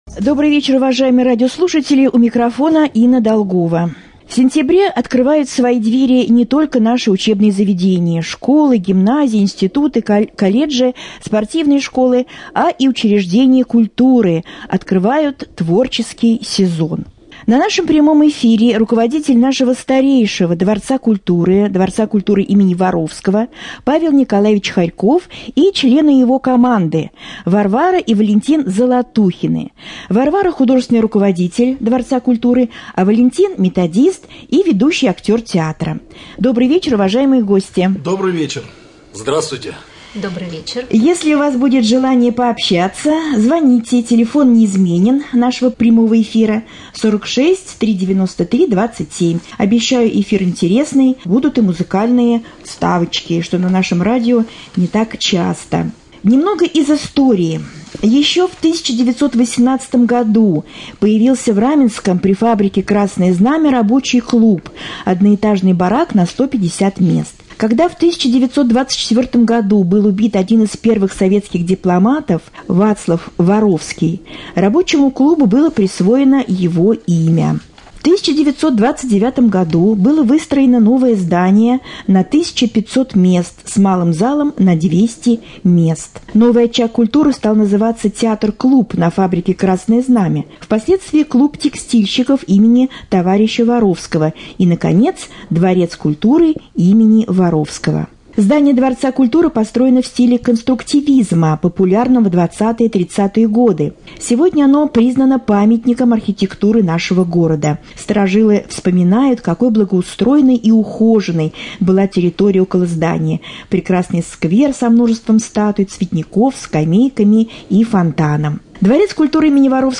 Прямой эфир